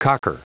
Prononciation du mot cocker en anglais (fichier audio)
Prononciation du mot : cocker